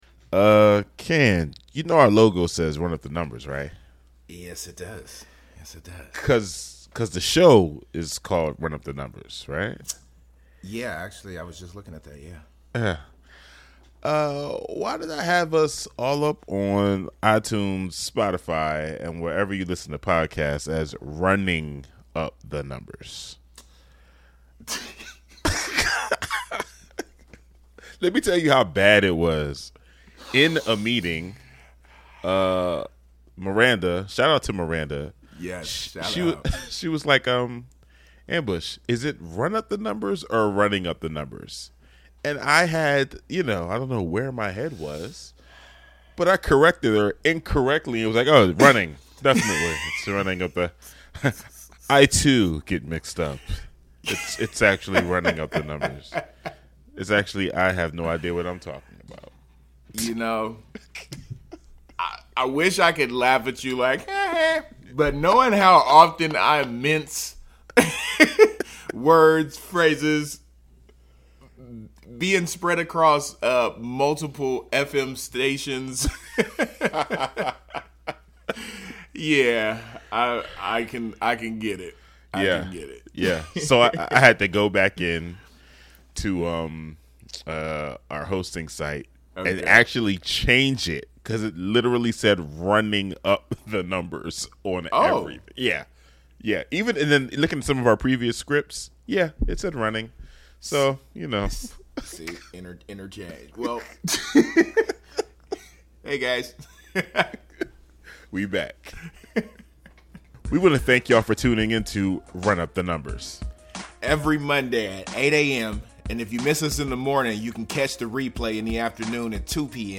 A segment of the XRAY morning show interview with Mingus Mapps